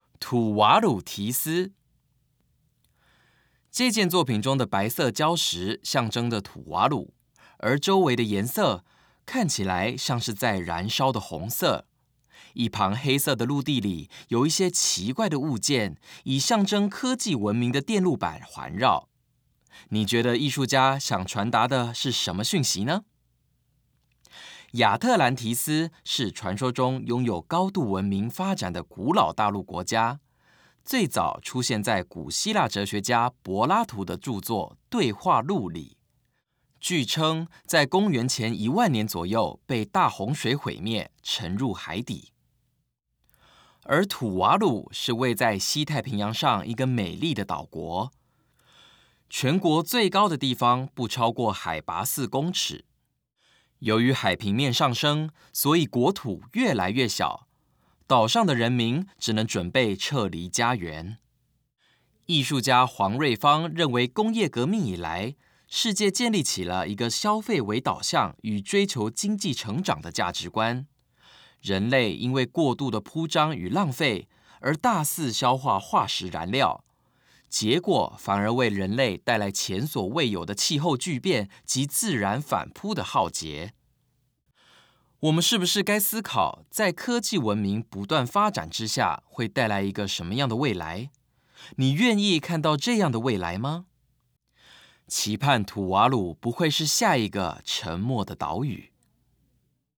語音導覽